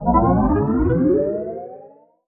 MAGIC_SPELL_Bending_Synth_Climb_stereo.wav